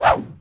FootSwing7.wav